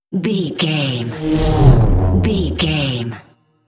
Scifi pass by vehicle
Sound Effects
futuristic
pass by
vehicle